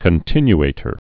(kən-tĭny-ātər)